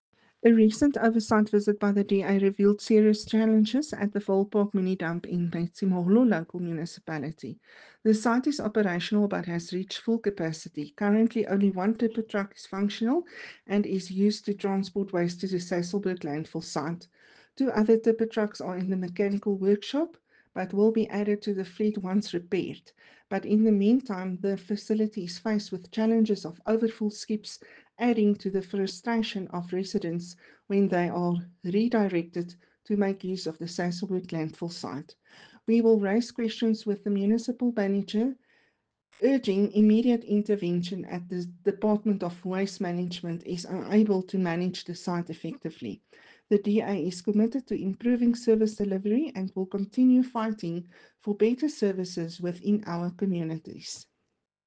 Afrikaans soundbites by Cllr Ruanda Meyer and Sesotho soundbite by Jafta Mokoena MPL.